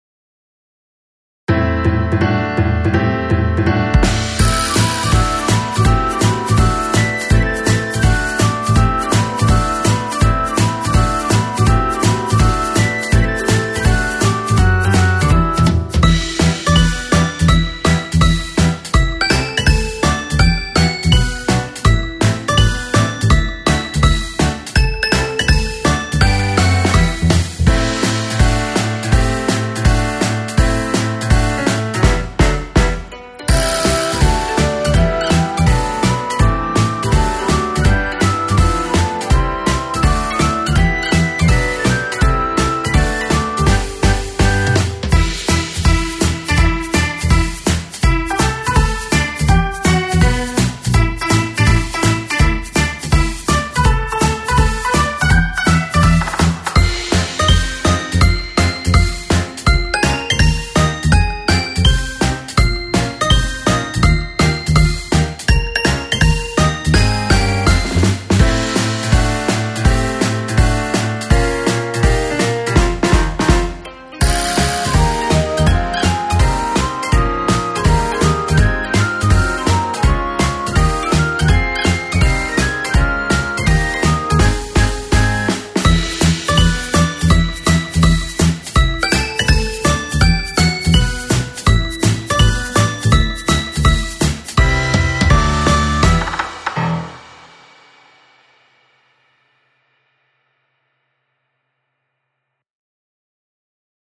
BGM
アップテンポショート